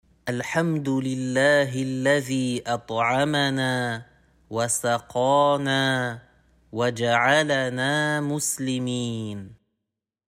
Invocation à dire après manger